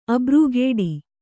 ♪ abrūgēḍi